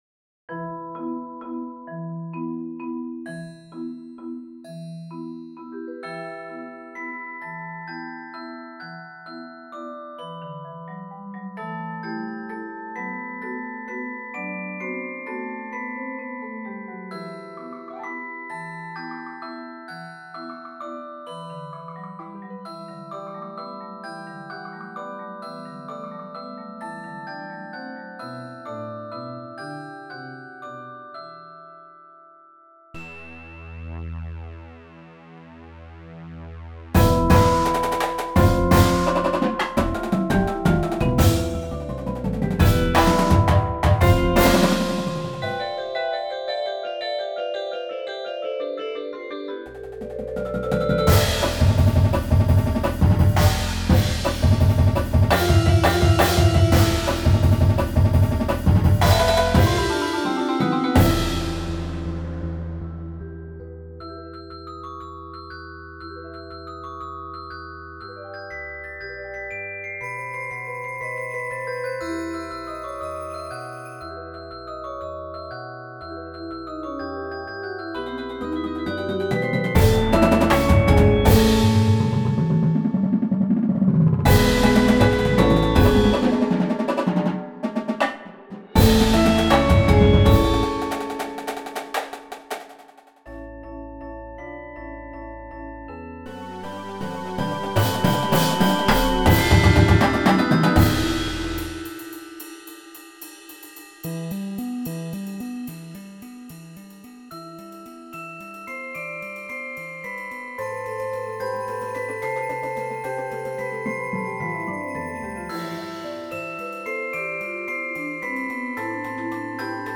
a modern Indoor Percussion Show